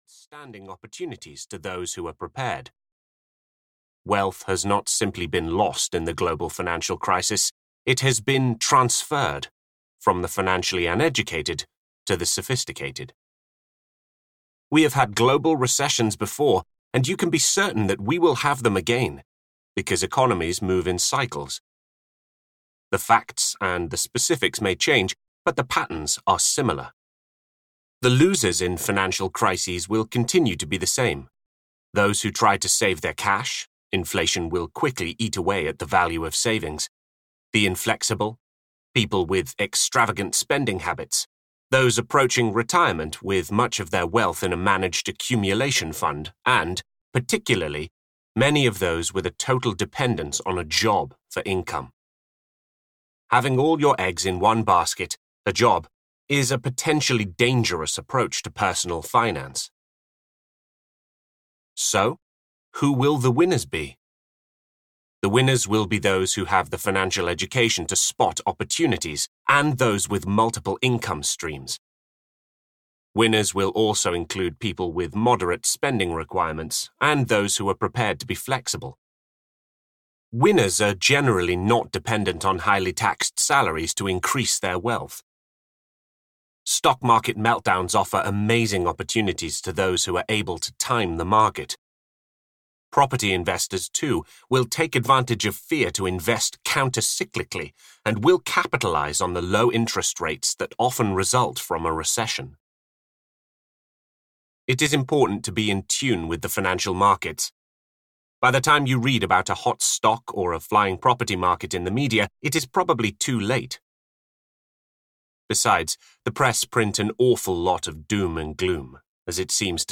Audio knihaGet a Financial Grip: A Simple Plan for Financial Freedom (EN)
Ukázka z knihy